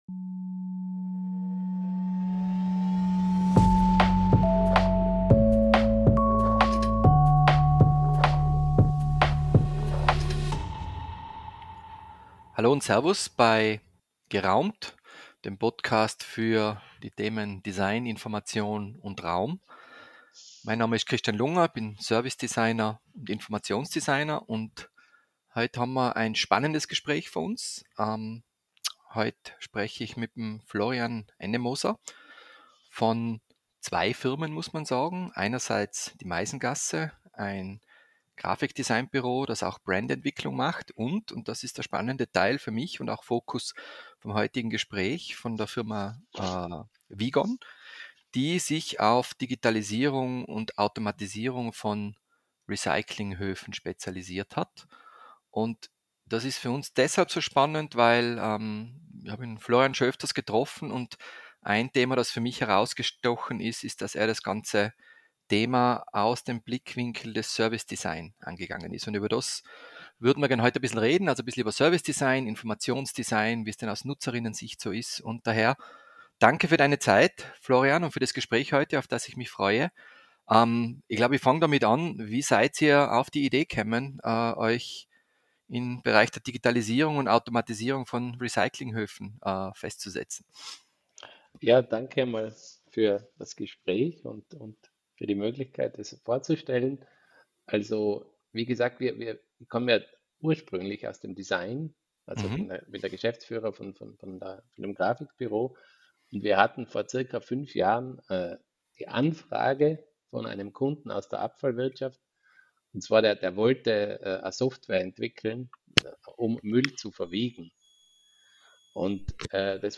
Informative Gespräche geben Einblick in die Welt der räumlichen Orientierung von Menschen in Gebäuden, Orten und im Naturraum.